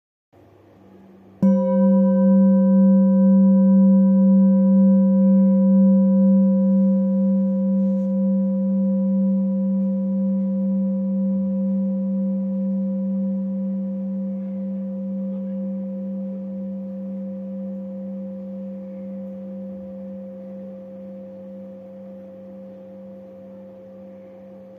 Lingam Singing Bowl-25897
Material Bronze
Lingam bowls have generally focused energy and dependable reverberation and sounds.